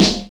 GVD_snr (41).wav